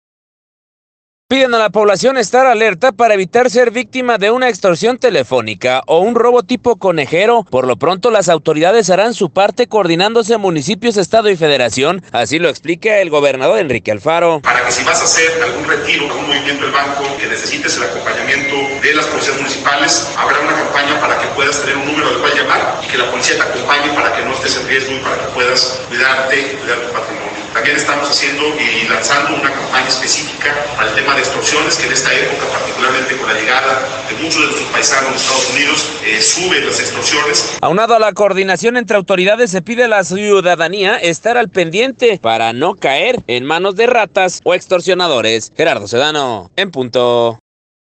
Piden a la población estar alerta para evitar ser víctima de una extorsión telefónica o un robo tipo conejero, por lo pronto, las autoridades harán su parte coordinándose municipios, estado y Federación, así lo explica el gobernador Enrique Alfaro: